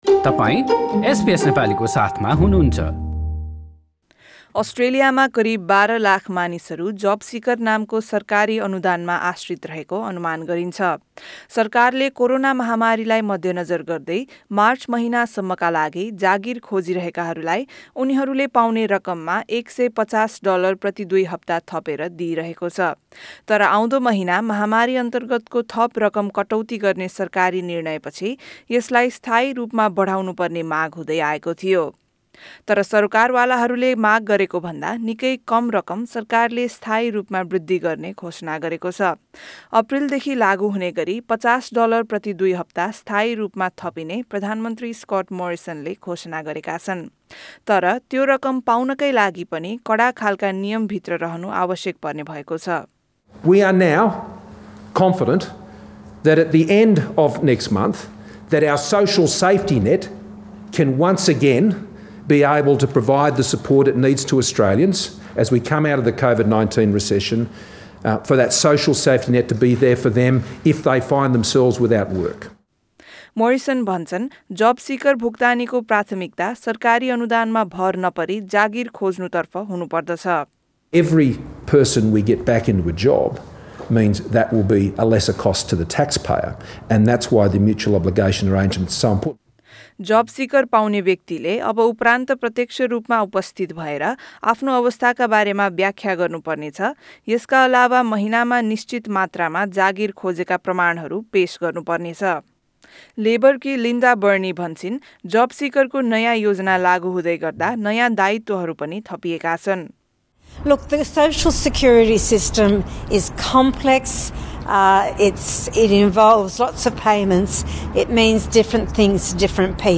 This news report is available in the Nepali language version of our website.